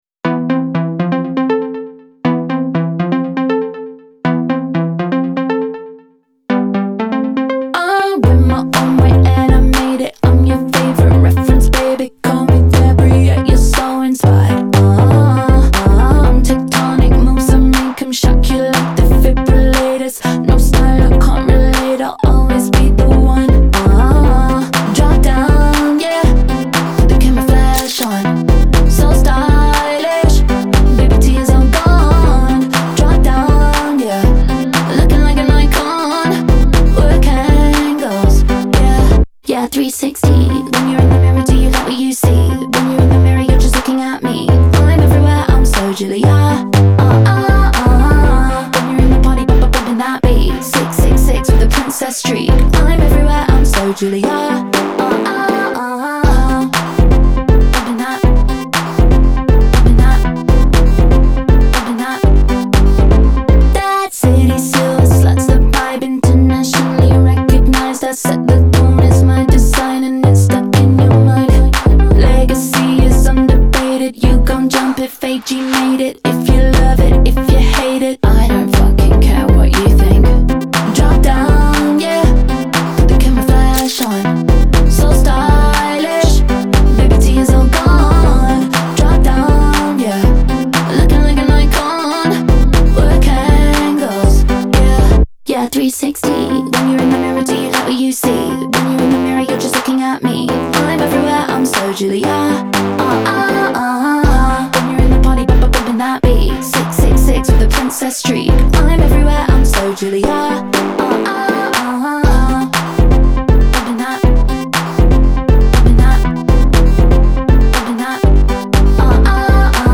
Electropop, Hyperpop, Club-Pop